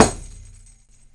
DOIRA 1A.WAV